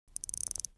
جلوه های صوتی
دانلود صدای ساعت 16 از ساعد نیوز با لینک مستقیم و کیفیت بالا
برچسب: دانلود آهنگ های افکت صوتی اشیاء دانلود آلبوم صدای ساعت از افکت صوتی اشیاء